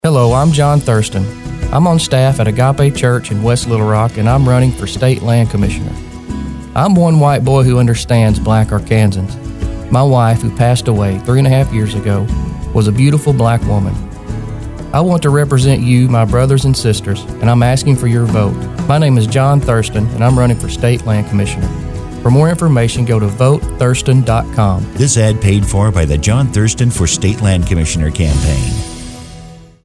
Show of hands: how many of you expected these two ads in a race for Arkansas Land Commissioner?